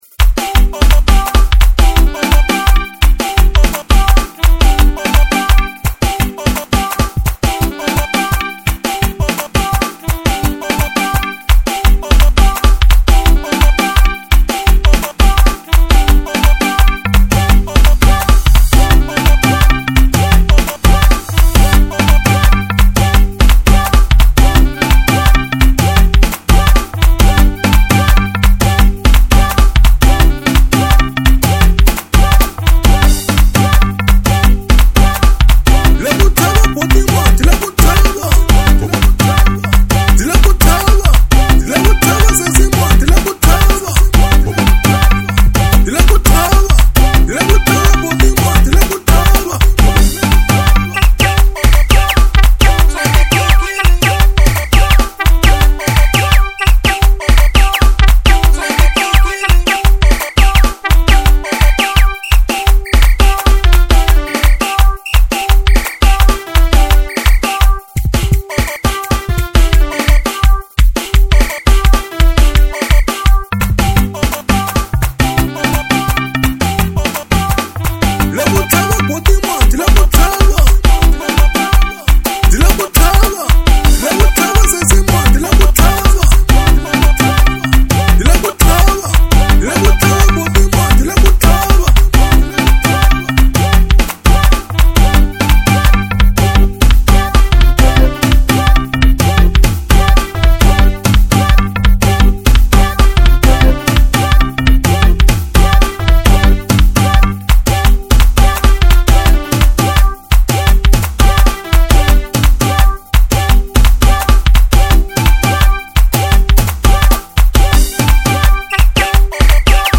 04:38 Genre : Xitsonga Size